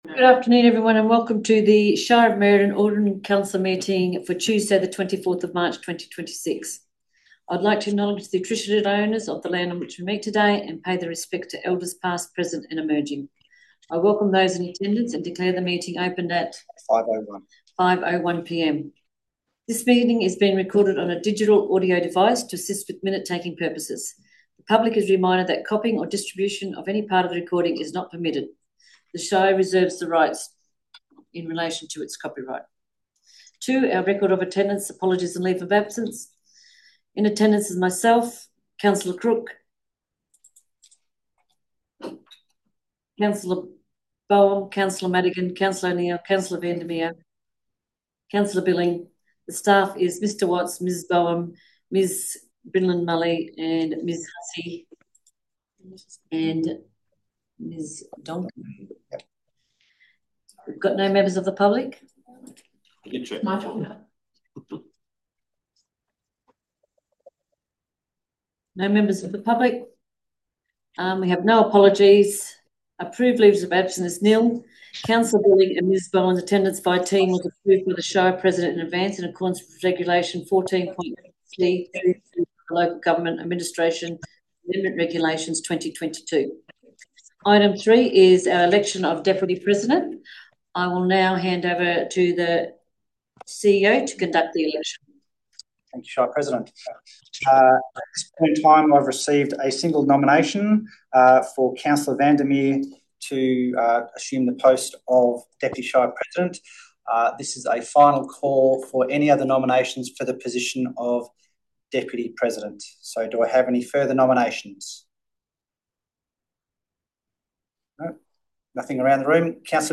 March Ordinary Council Meeting » Shire of Merredin